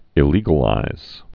(ĭ-lēgə-līz)